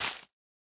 Minecraft Version Minecraft Version 1.21.5 Latest Release | Latest Snapshot 1.21.5 / assets / minecraft / sounds / block / spore_blossom / break3.ogg Compare With Compare With Latest Release | Latest Snapshot
break3.ogg